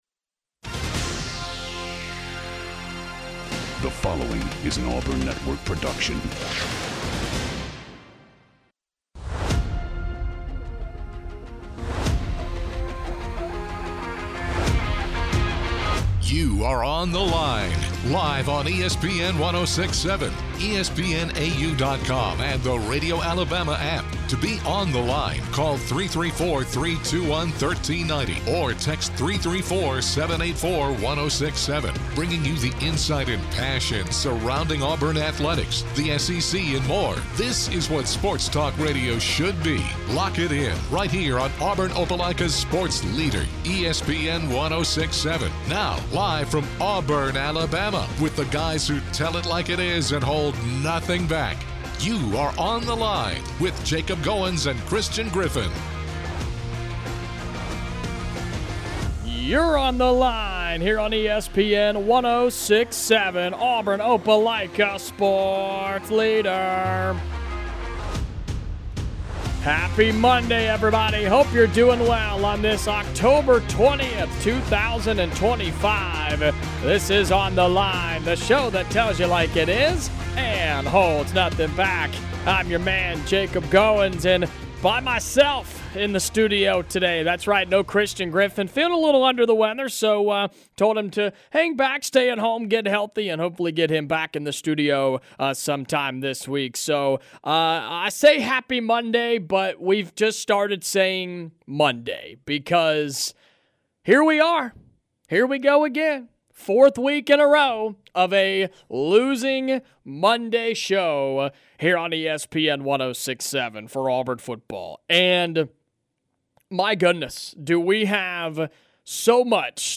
Is it time to move on from Hugh Freeze as head coach? He takes calls and texts from listeners who give their takes on the four-game losing streak for Auburn as they express concerns with the offense.